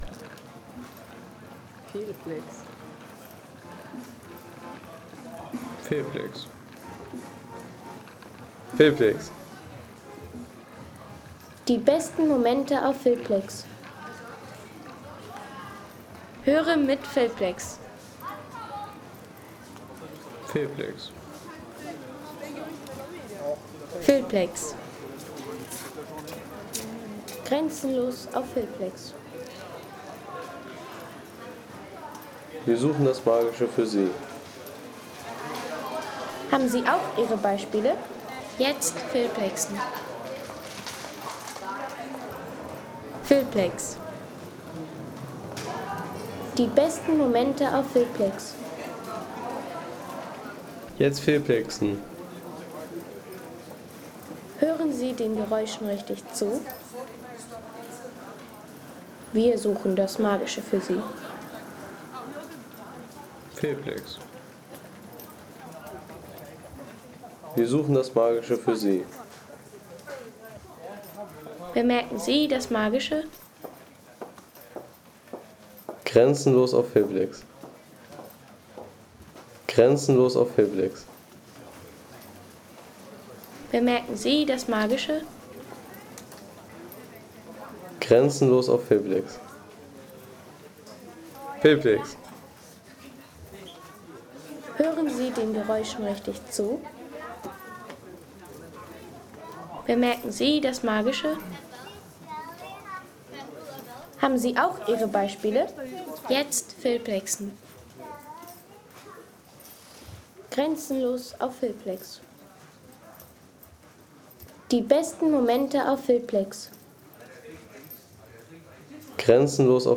Flughafen in Kopenhagen
Internationaler dänischer Flughafen in Kopenhagen.